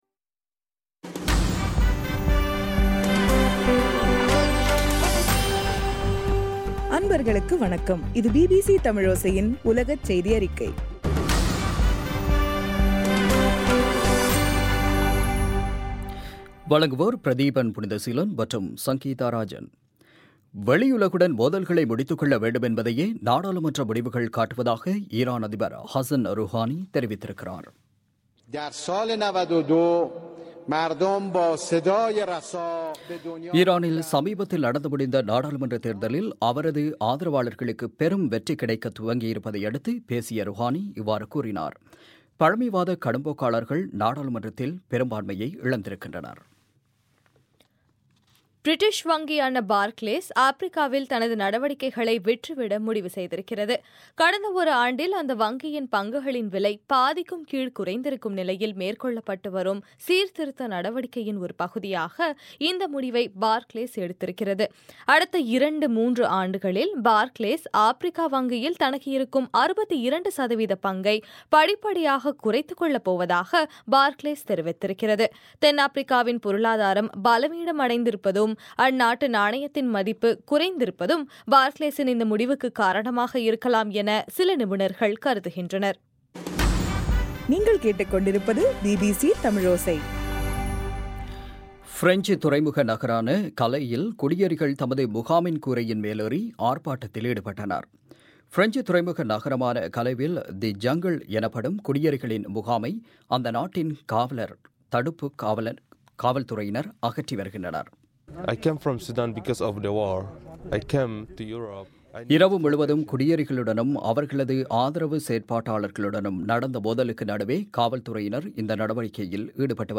இன்றைய ( மார்ச் 1) பிபிசி தமிழோசை செய்தியறிக்கை